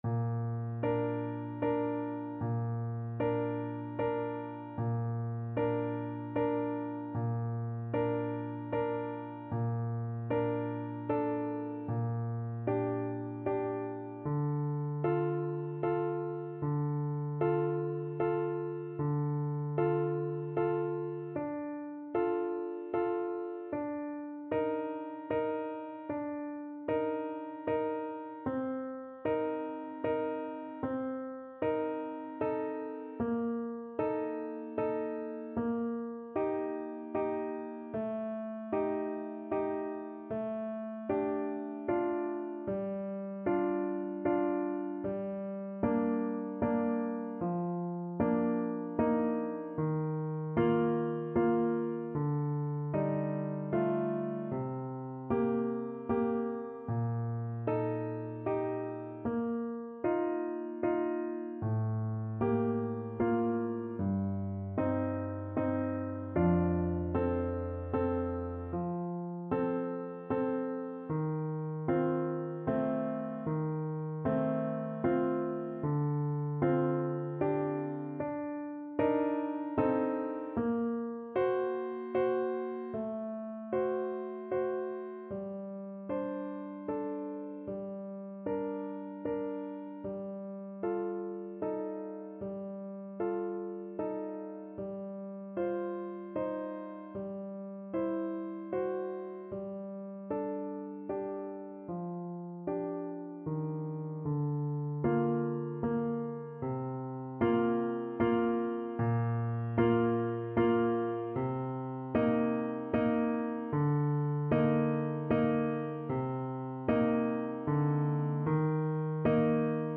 Bb major (Sounding Pitch) F major (French Horn in F) (View more Bb major Music for French Horn )
Adagio assai =76
Classical (View more Classical French Horn Music)
ravel_piano_con_2nd_mvt_HN_kar1.mp3